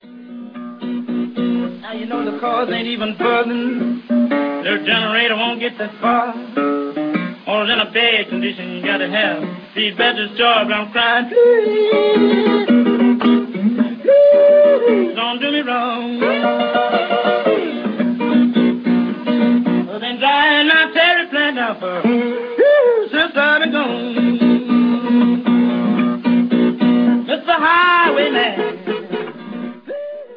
Mean Black Cat Blues - блюзовая архаика